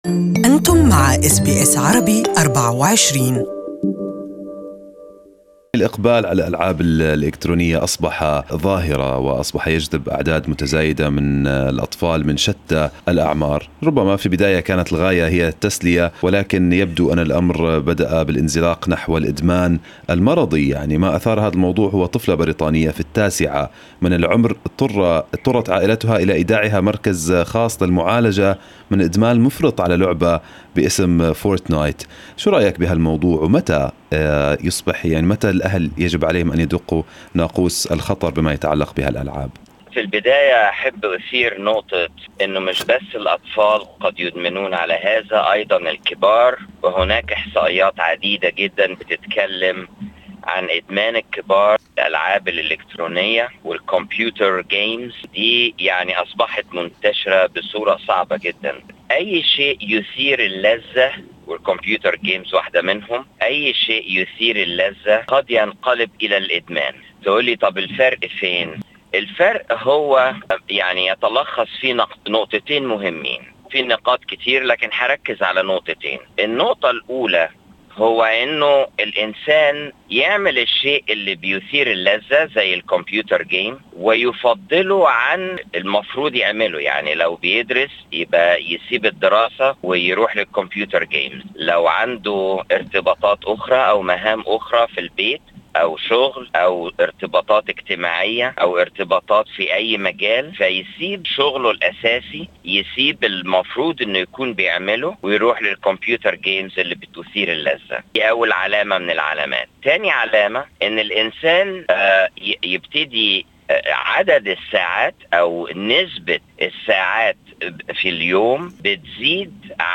Good Morning Australia interviewed psychiatrist